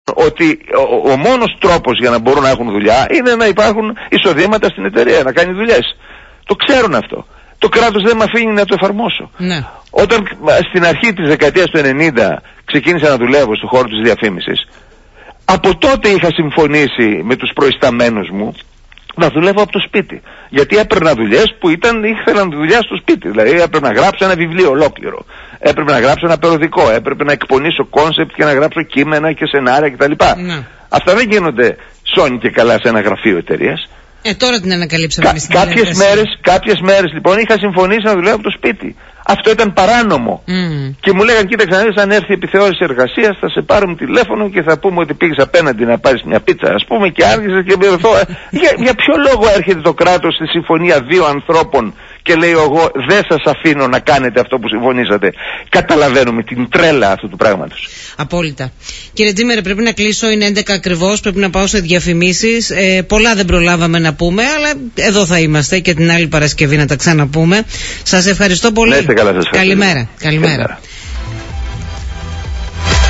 Ακούστε εδώ ολόκληρη την συνέντευξη του προέδρου του πολιτικού κόμματος “Δημιουργία Ξανά” Θάνου Τζήμερου στον Politica 89.8: